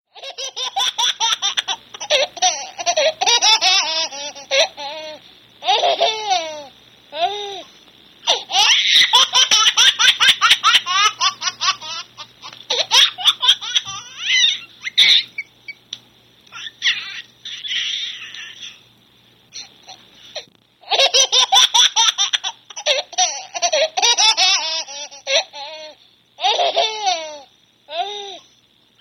Baby laughter ringtone free download